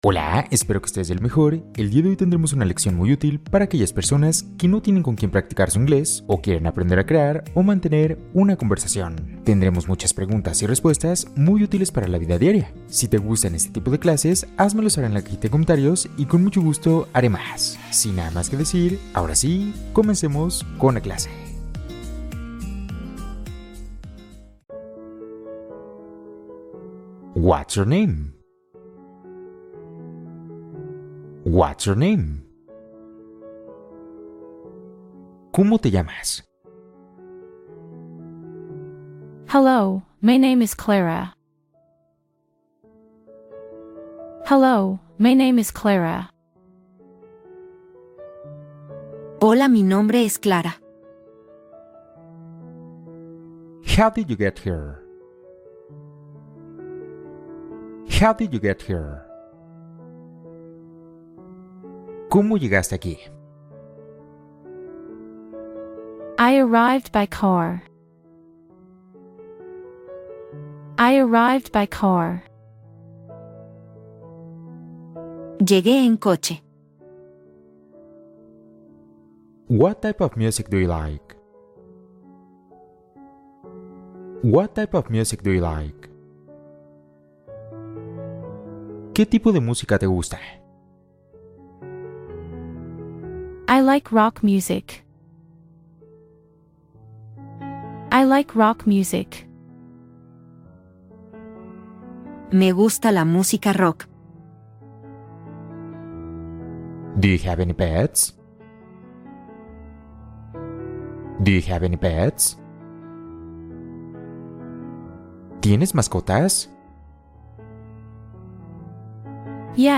Ejercicio diario de escucha para mejorar tu nivel de inglés